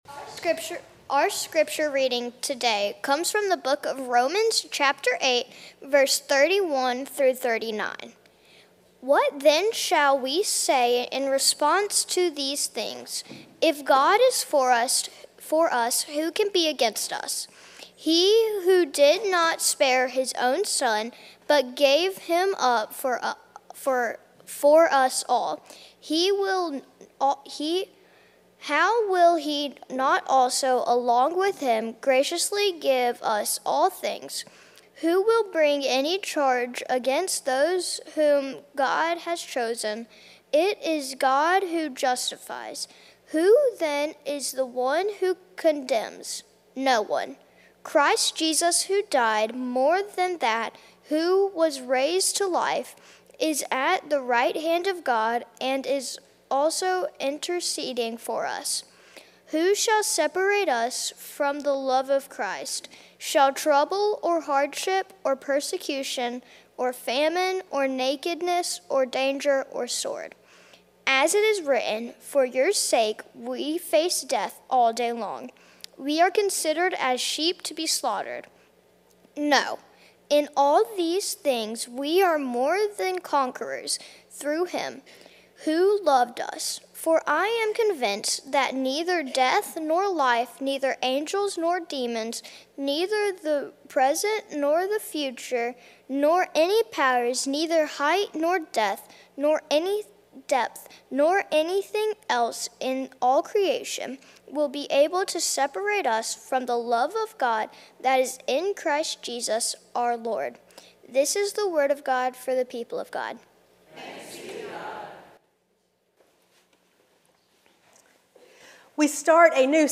Passage: 1 Samuel 17:40-51 Service Type: Traditional Service